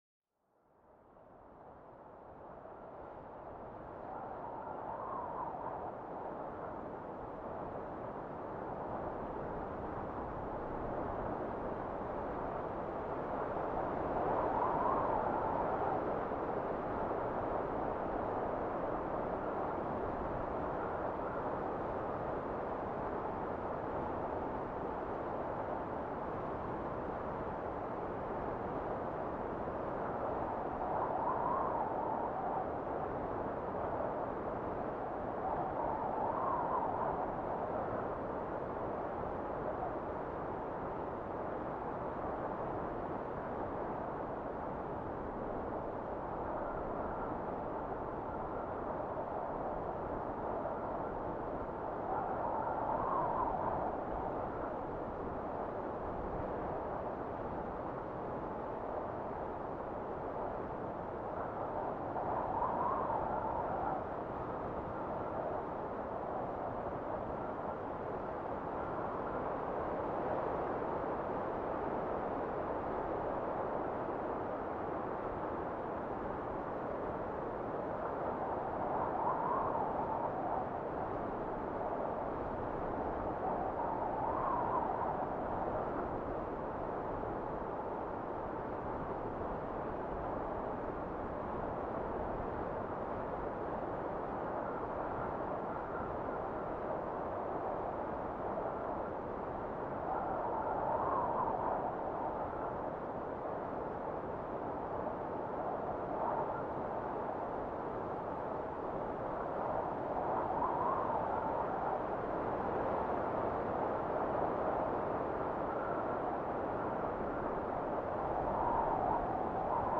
Sumérgete en una experiencia auditiva única donde el susurro del viento se transforma en una sinfonía natural.